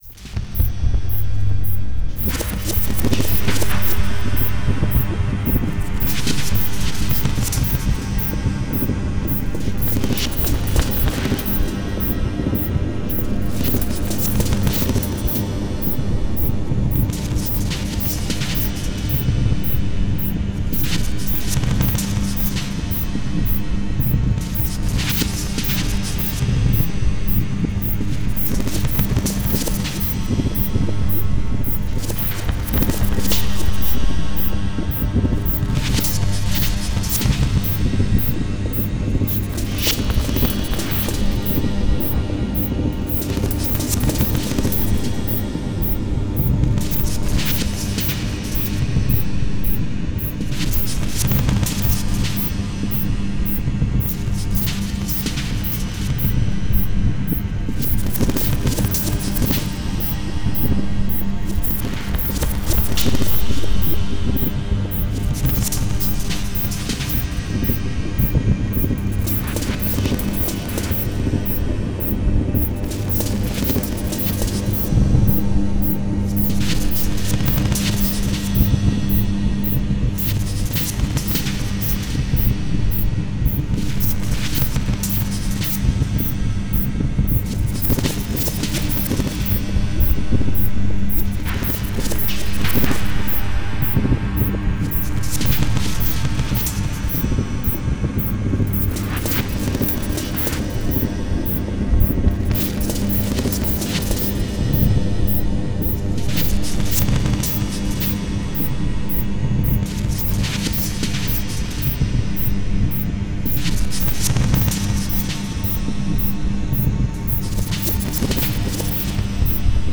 複雑な打楽器のループ・シーケンス（"Pulse"）と、
豊富な倍音を含んだ通奏持続音（"Drone"）の組み合わせが、
超音波帯域を含んで、可聴域を超える高周波から低周波までの全帯域で、
たいへん複雑・緻密なエンベロープ、パンニング、倍音のコントロールが施されている。